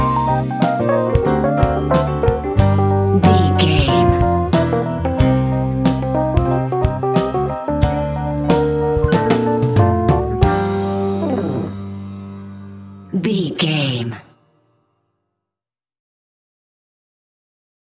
Fast paced
Uplifting
Ionian/Major
hip hop